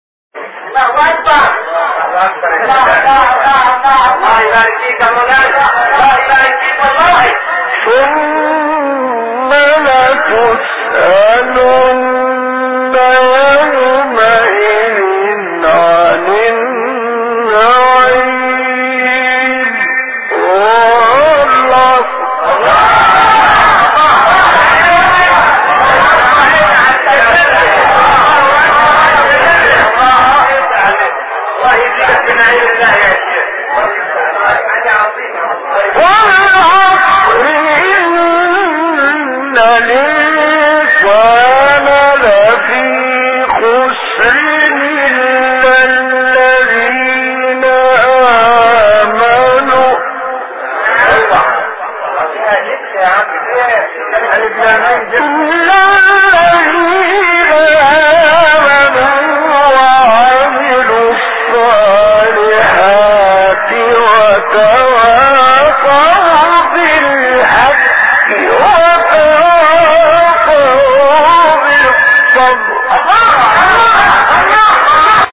برچسب ها: خبرگزاری قرآن ، ایکنا ، فعالیتهای قرآنی ، مقاطع صوتی ، فراز صوتی ، تلاوت ، راغب مصطفی غلوش ، شحات محمد انور ، محمد الفیومی ، محمود شحات انور ، محمد عبدالعزیز حصان ، متولی عبدالعال ، مقطع میلیونی مصطفی اسماعیل ، قرآن